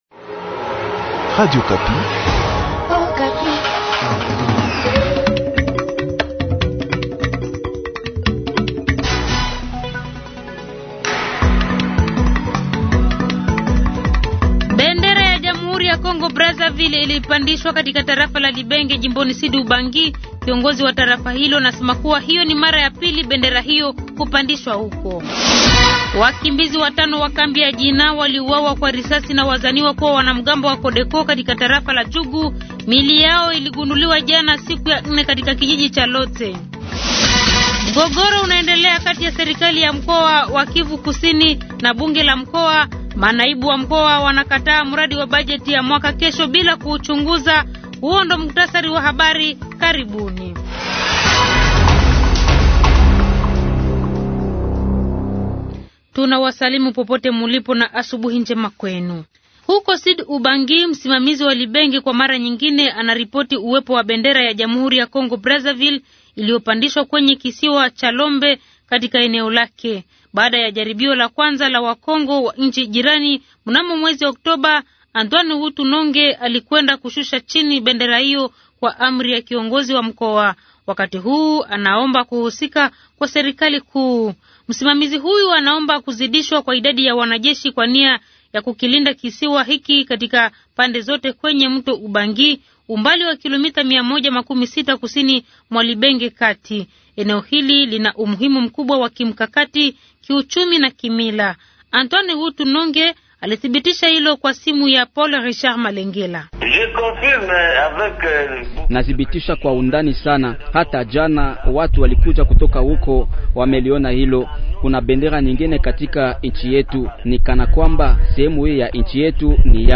Journal du matin